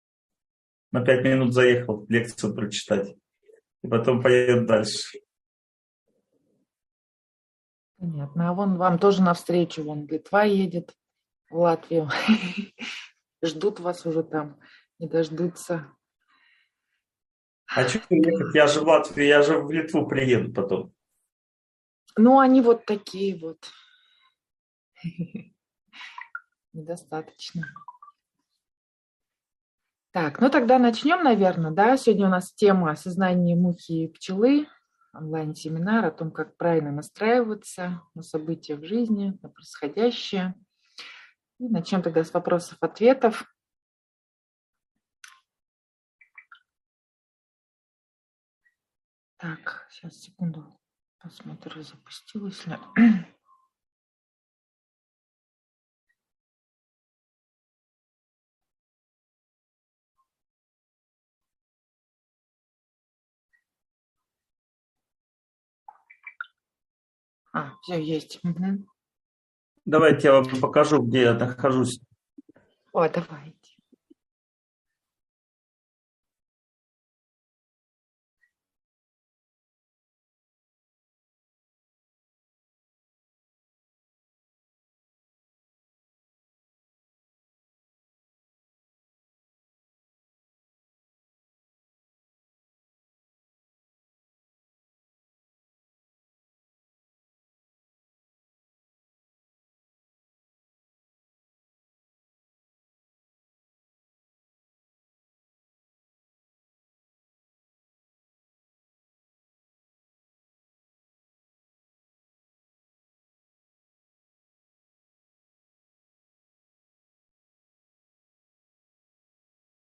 Сознание мухи и пчелы. Как правильно настроиться на происходящее? (онлайн-семинар, 2023)